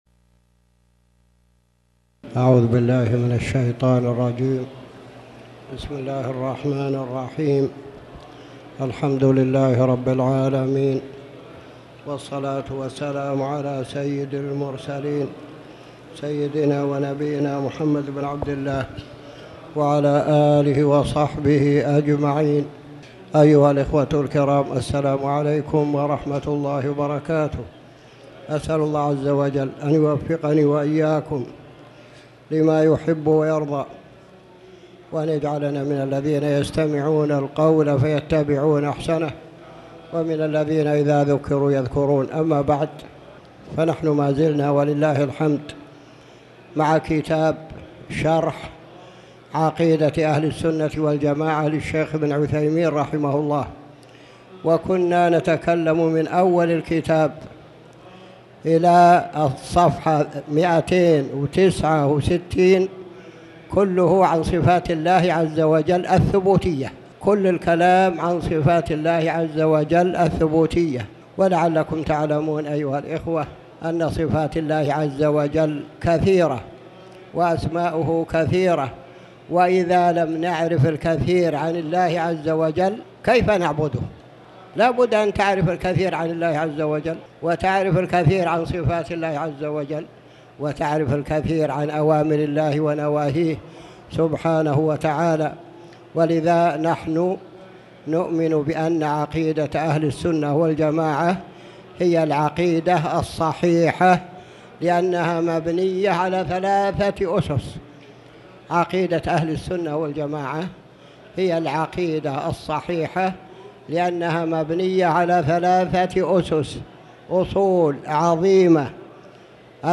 تاريخ النشر ١٥ صفر ١٤٣٩ هـ المكان: المسجد الحرام الشيخ